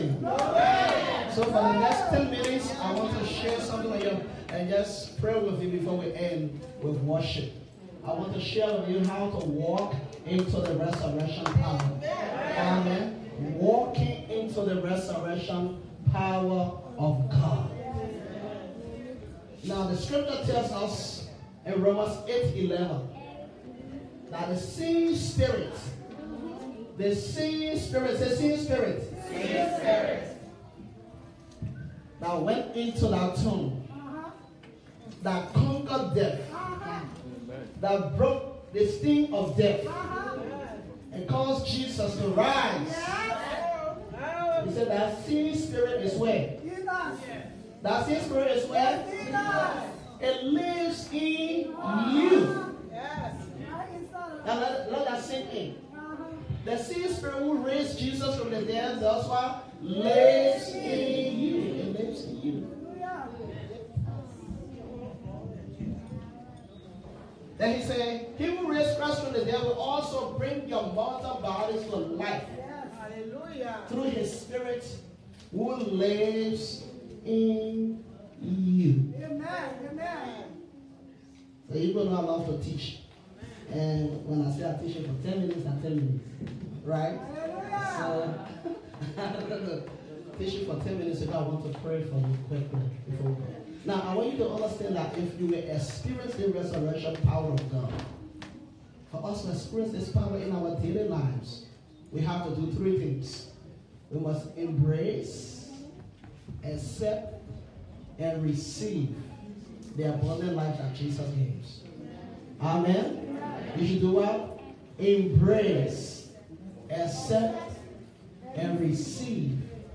Roman 8:11 Video Sermon Audio Sermon